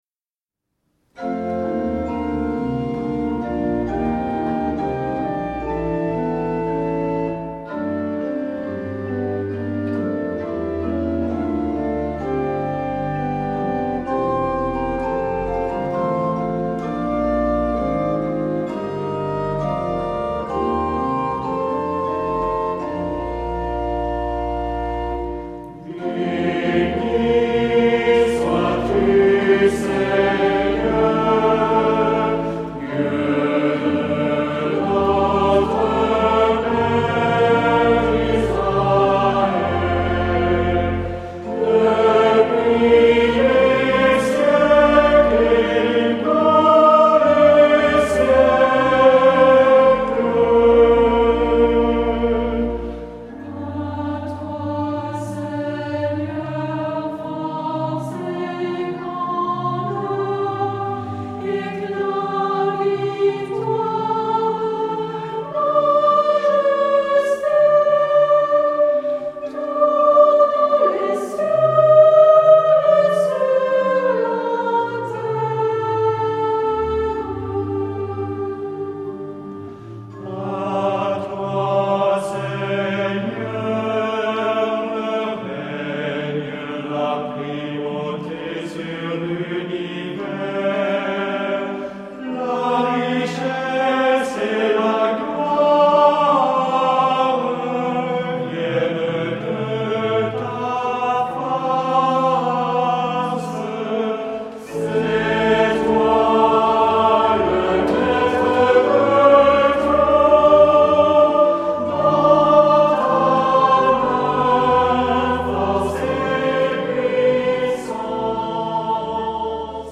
Genre-Style-Forme : Canticle
Caractère de la pièce : vivant ; majestueux
Type de choeur :  (1 voix unisson )
Instrumentation : Orgue  (1 partie(s) instrumentale(s))
Tonalité : si bémol majeur
Usage liturgique : Chant de louange ; Action de grâces